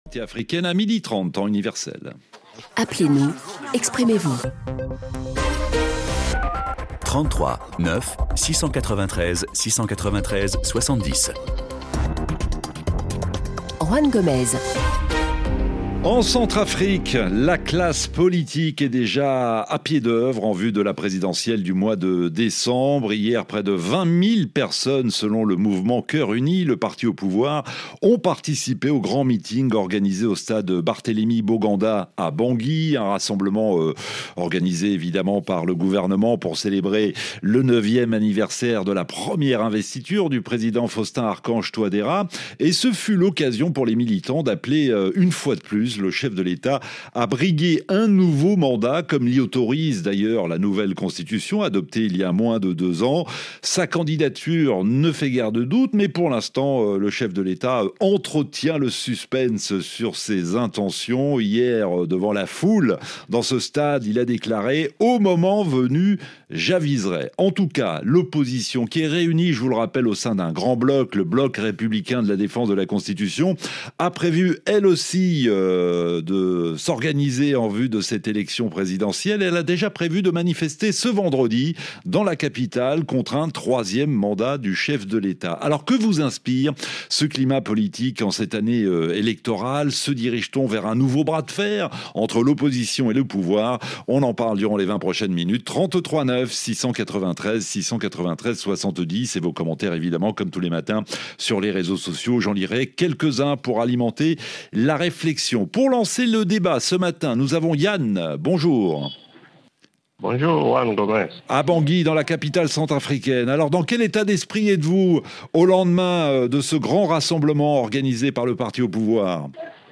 Le rendez-vous interactif des auditeurs de RFI.